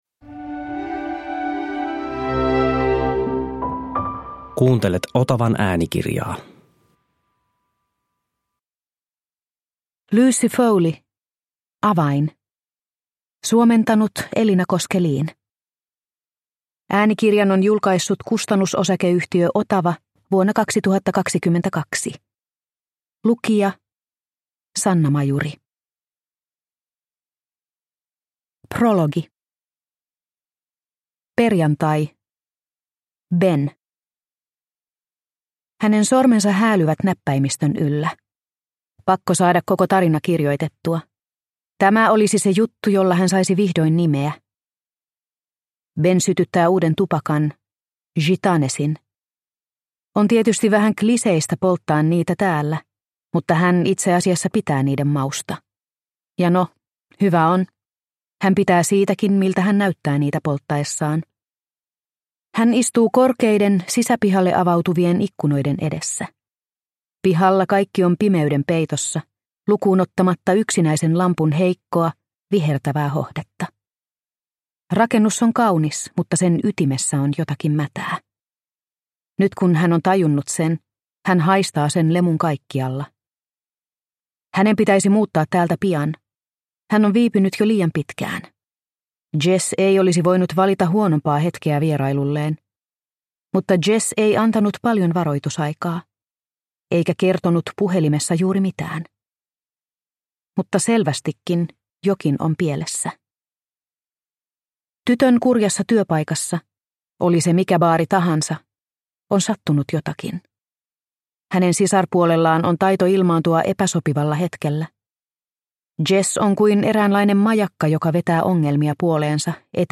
Avain – Ljudbok – Laddas ner